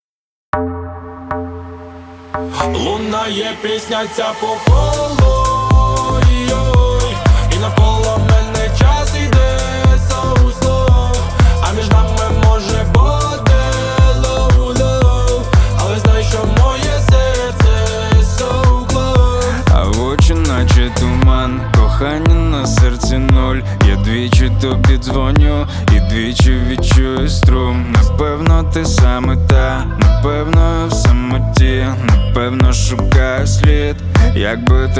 Жанр: Поп / Украинские